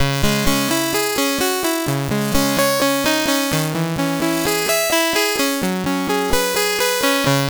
Less Epic Pad Cm 128.wav